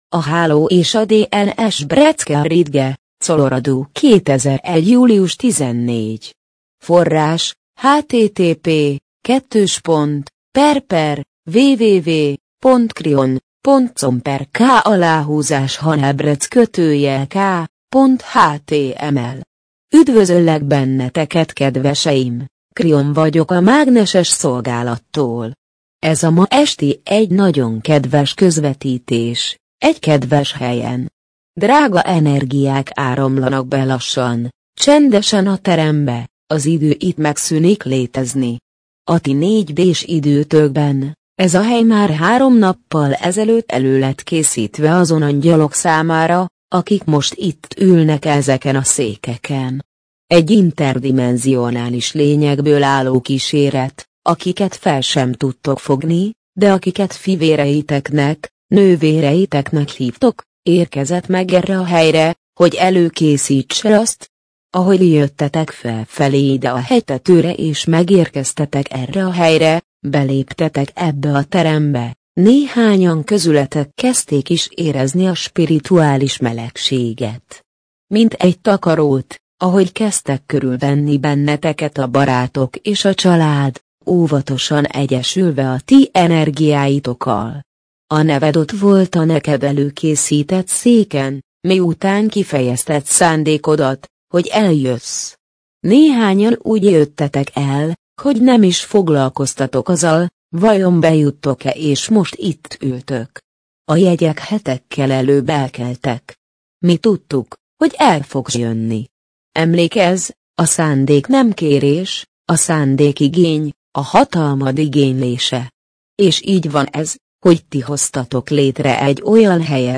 MP3 gépi felolvasás A Háló és a DNS A Háló és a DNS Breckenridge, Colorado - 2001. július 14.